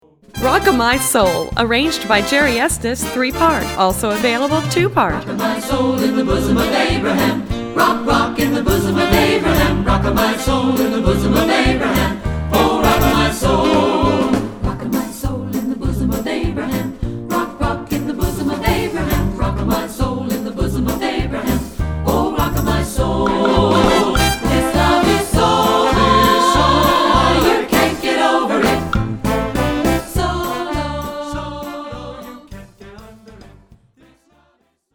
Composer: Spiritual
Voicing: Accompaniment CD